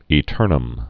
(ē-tûrnəm)